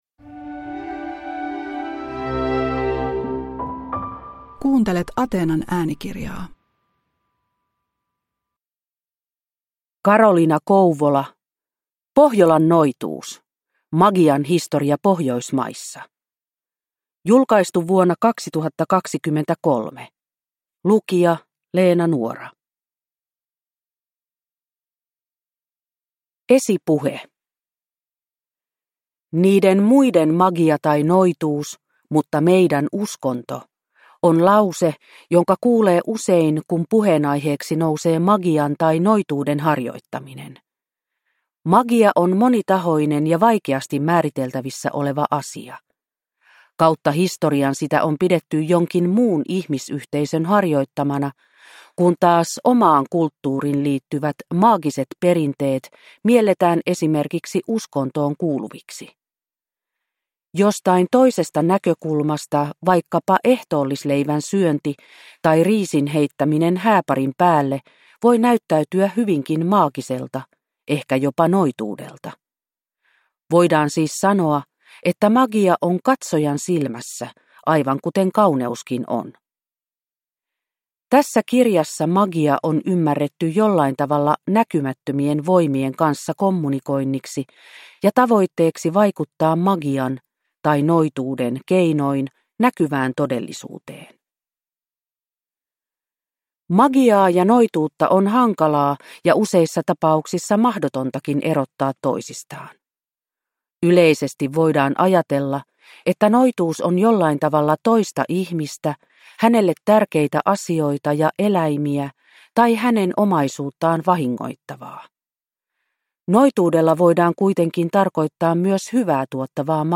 Pohjolan noituus – Ljudbok